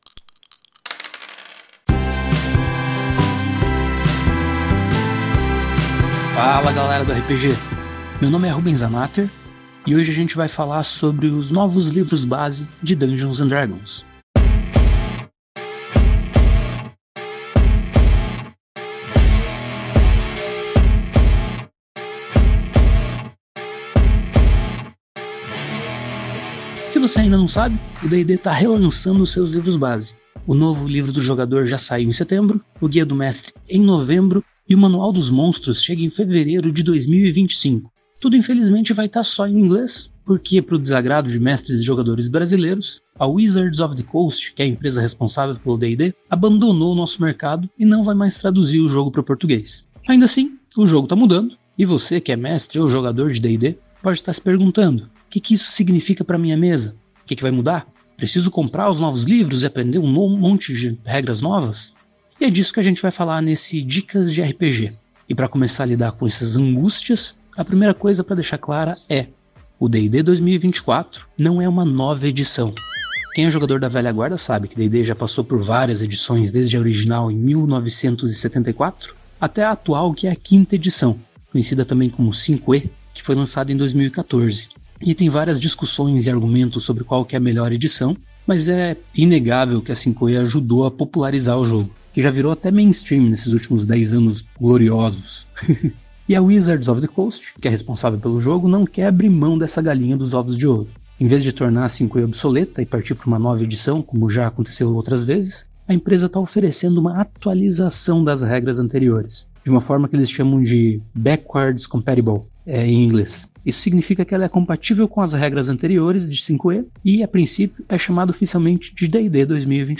Músicas: Music by from Pixabay